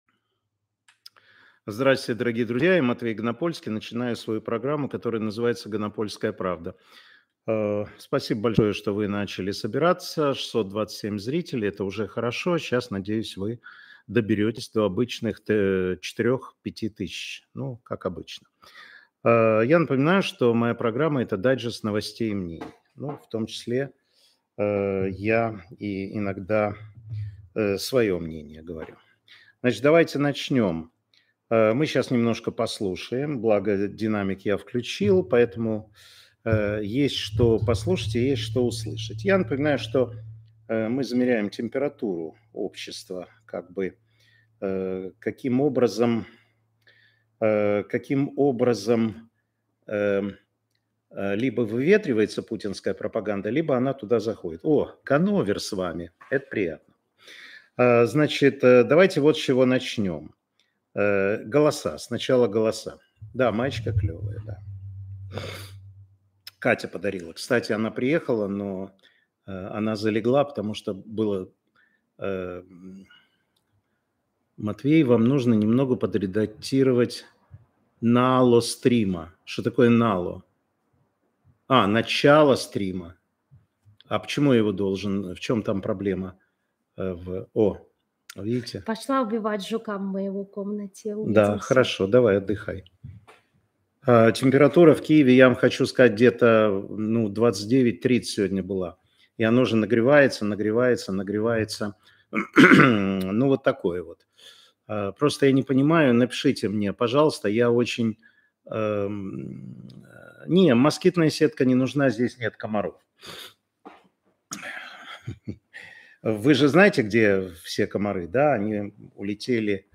Эфир Матвея Ганапольского